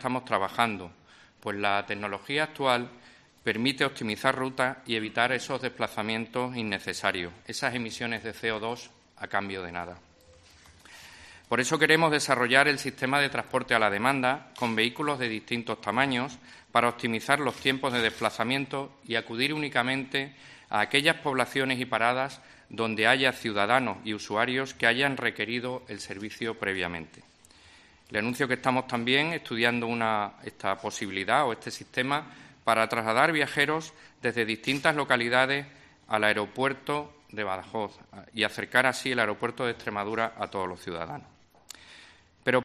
Así lo ha manifestado el director general de Movilidad y Transportes, Cristóbal Maza Olivera.
De este modo lo ha señalado este miércoles en comisión parlamentaria en la Asamblea, a pregunta de Unidas por Extremadura sobre la cuestión, el director general de Movilidad y Transportes de la Junta, Cristóbal Maza Olivera, quien ha defendido que la tecnología actual "permite optimizar rutas y evitar desplazamientos innecesarios, emisiones de CO2 a cambio de nada".